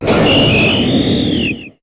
Cri de Silvallié dans sa forme Type : Normal dans Pokémon HOME.